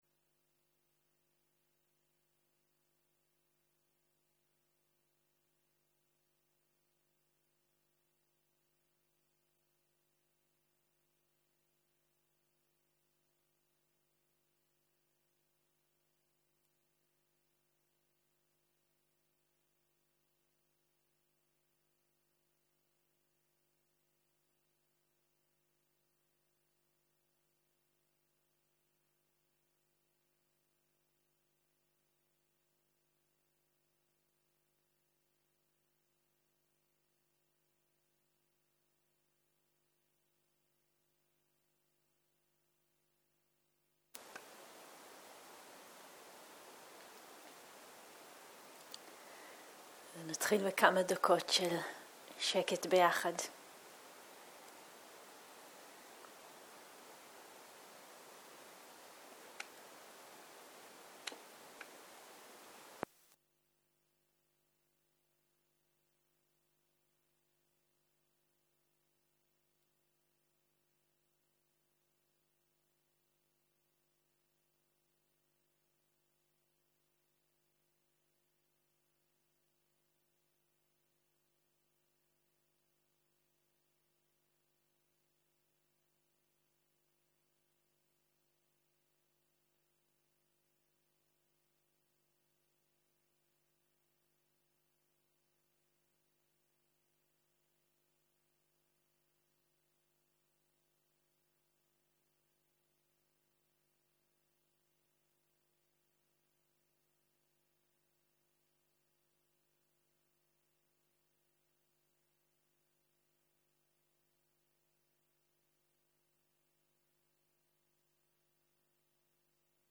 Dharma type: Dana Talk שפת ההקלטה